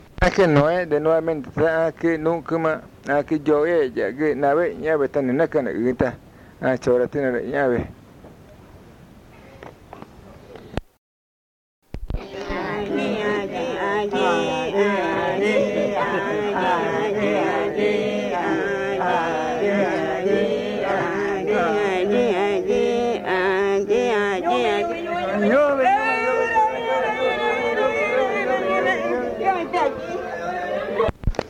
San Juan del Socó, río Loretoyacu, Amazonas (Colombia)